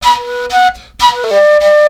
FLUT 01.AI.wav